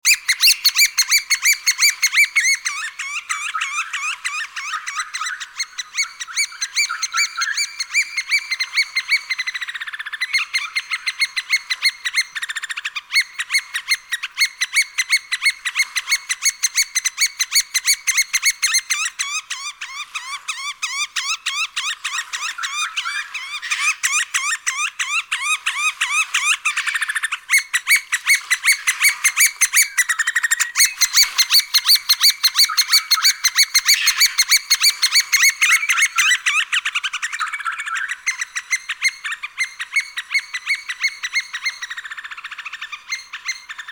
Звуки кулика
Звук пения кулика-сороки